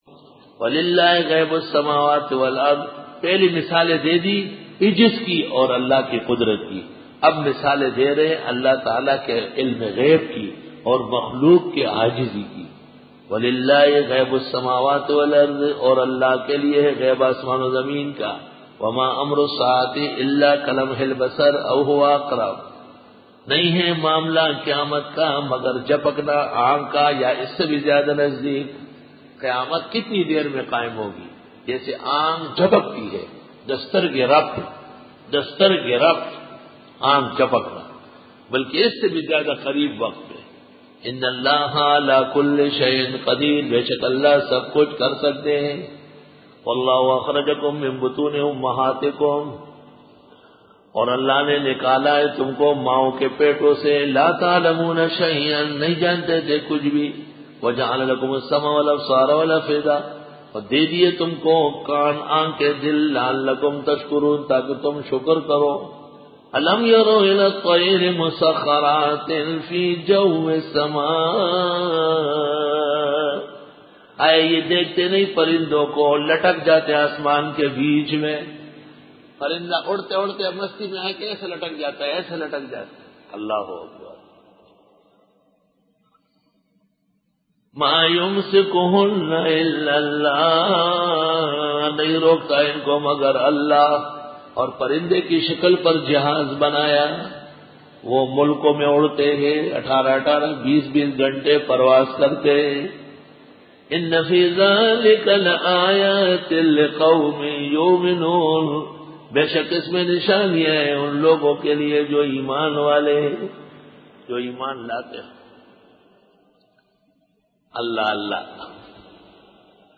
Dora-e-Tafseer 2005